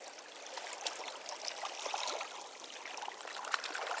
water.wav